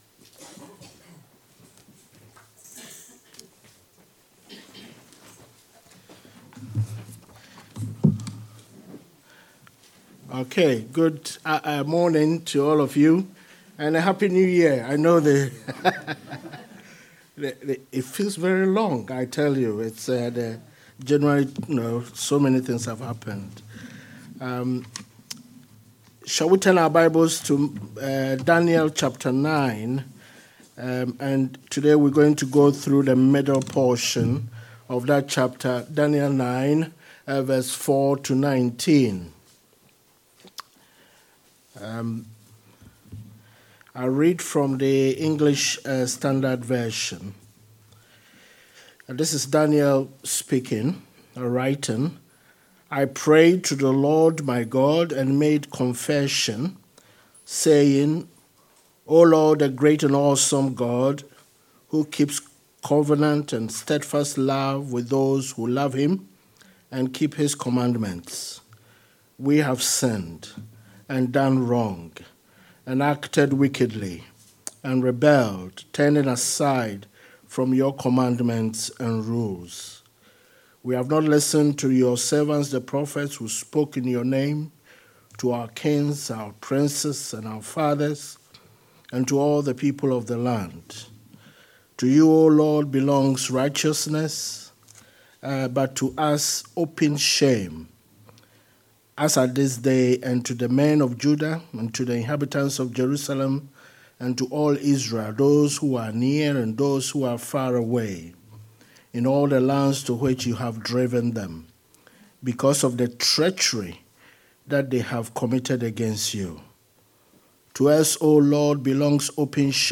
Welcome to our recording of this morning’s message, which is featured below.